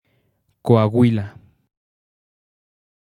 ^ Spanish pronunciation: [koaˈwila]